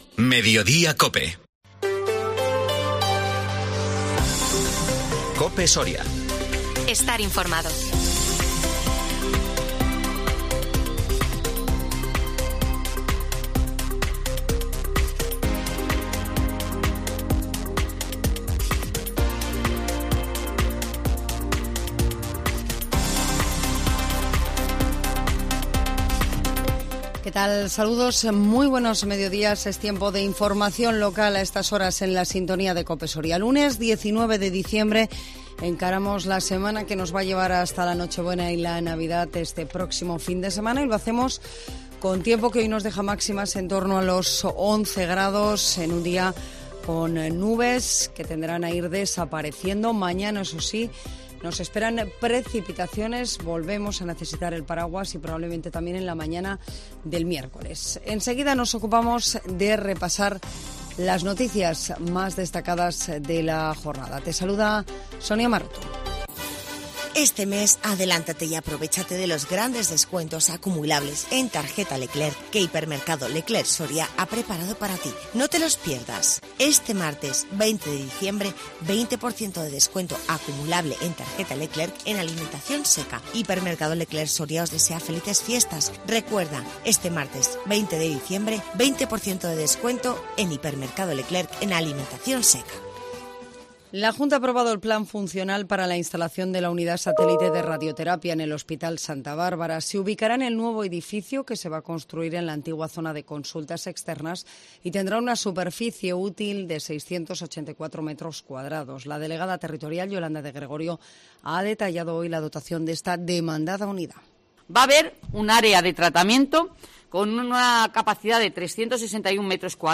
INFORMATIVO MEDIODÍA COPE SORIA 19 DICIEMBRE 2022